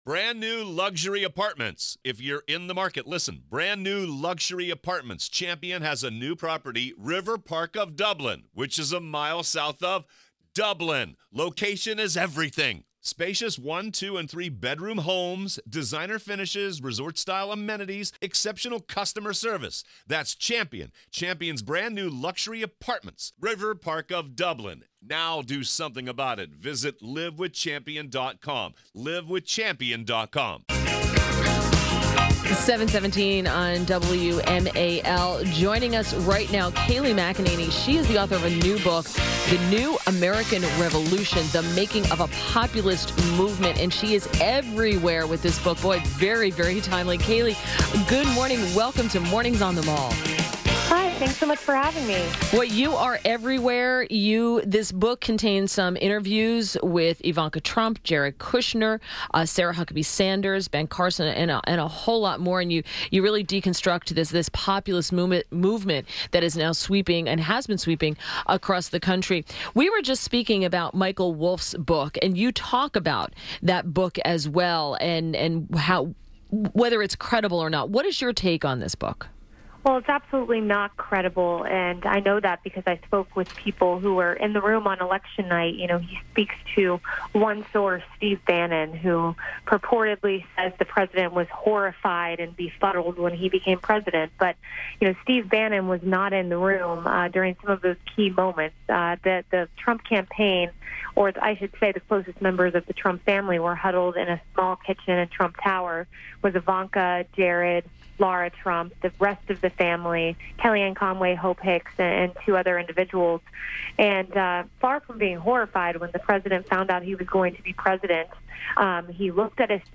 WMAL Interview - KAYLEIGH MCENANY - 01.11.18